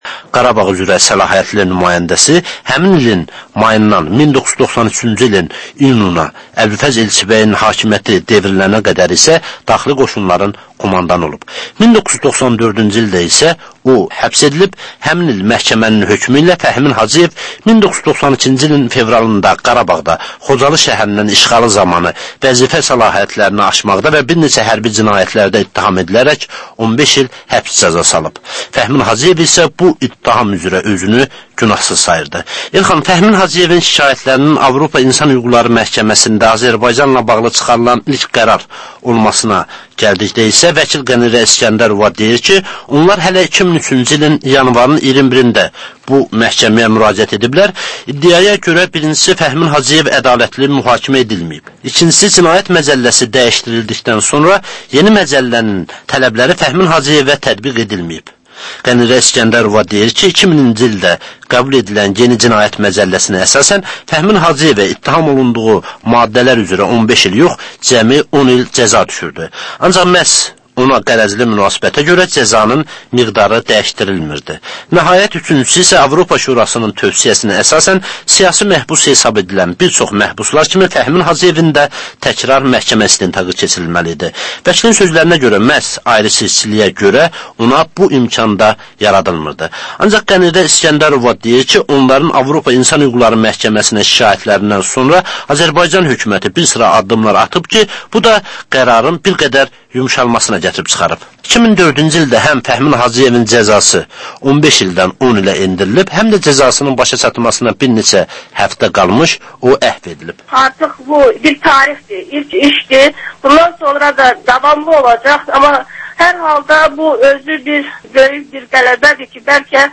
Reportaj, müsahibə, təhlil